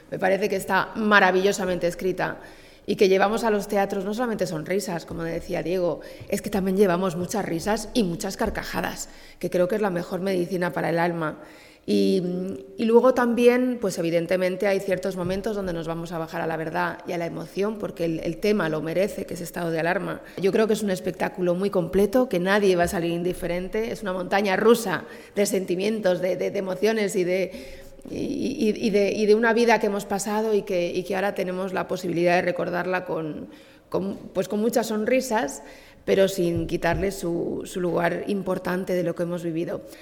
La actriz y presentadora, Lara DIbildos, cerraba la presentación de “Estado de alarma” tomando la palabra en la rueda de prensa para señalar que “es una obra maravillosamente escrita, ya que llevamos a los teatros risas y carcajadas, que creo es la mejor medicina para el alama.